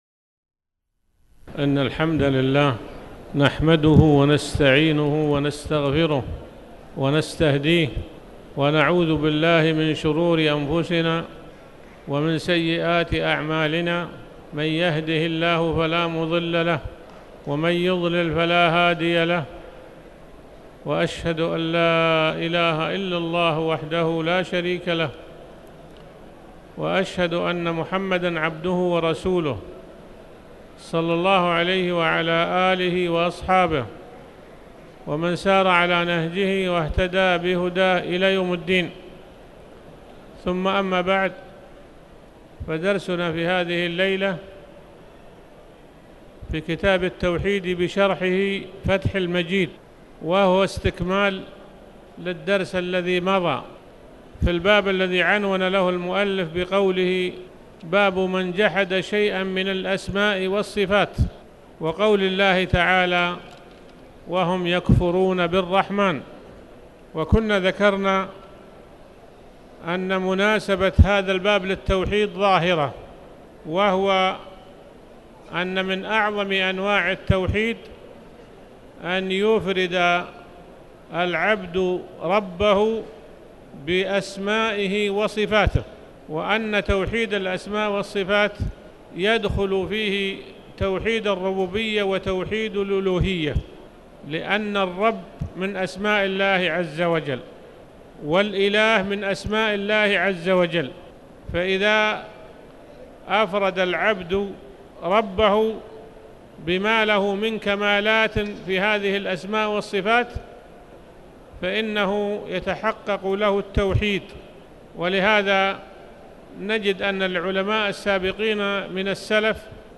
تاريخ النشر ١١ جمادى الآخرة ١٤٣٩ هـ المكان: المسجد الحرام الشيخ